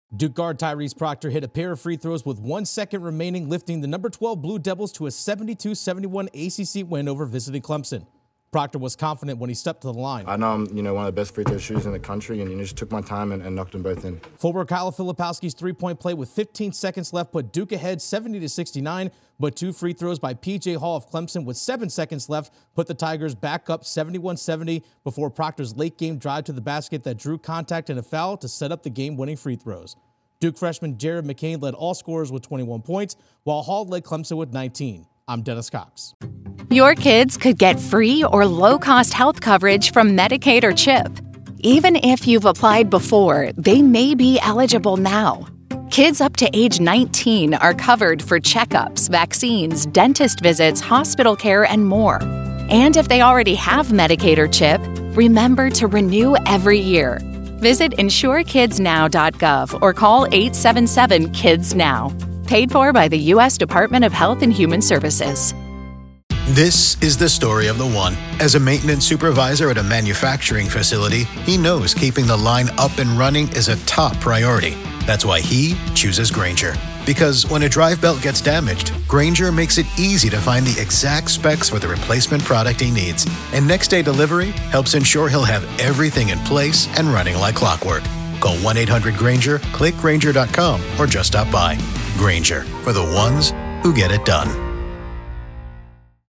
Duke pulls out a win over visiting Clemson. Correspondent